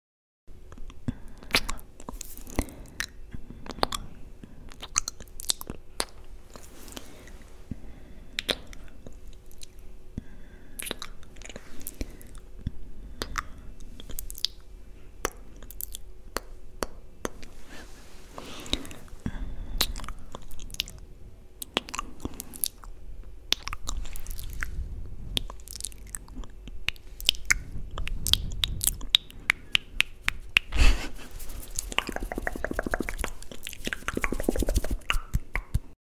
ASMR kisses and mouth sounds